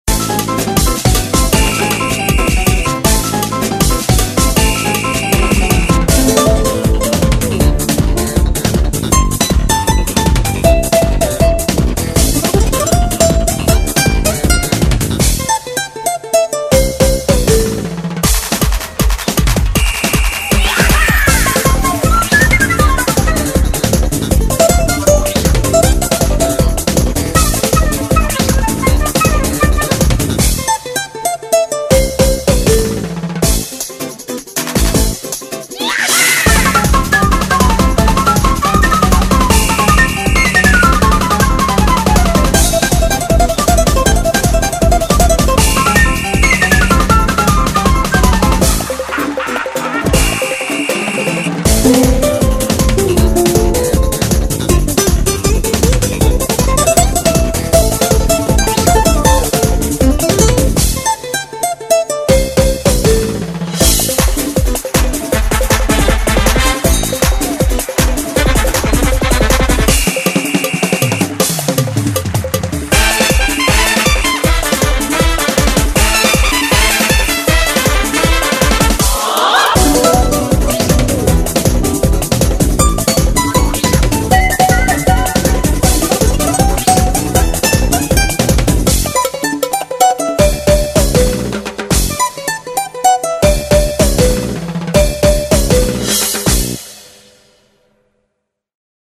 BPM157--1
Audio QualityPerfect (High Quality)